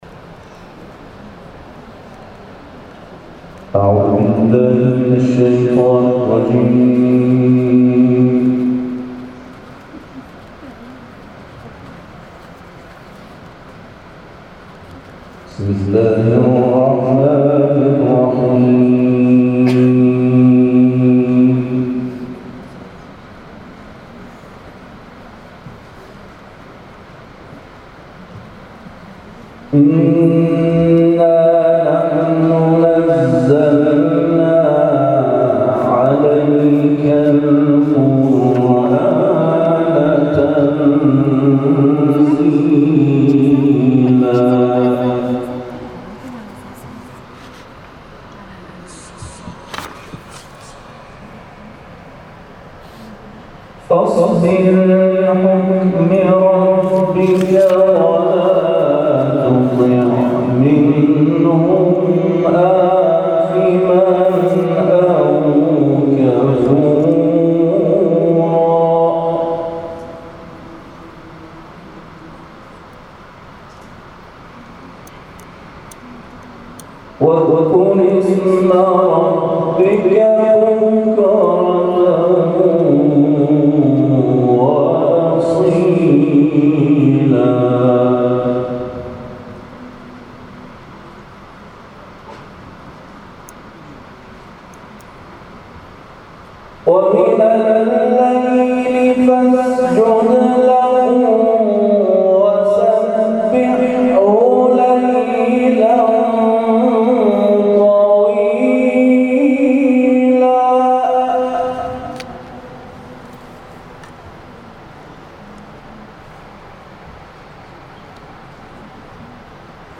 به گزارش خبرگزاری بین‌المللی قرآن(ایکنا)، مراسم افتتاحیه دهمین دوره مسابقات سراسری حفظ، قرائت و ترتیل دارالقرآن‌ امام علی(ع) ۲۷ شهریورماه در بخش بانوان در سالن همایش‌های مجموعه فرهنگی یادمان شهدای هفتم تیر(سرچشمه) برگزار شد.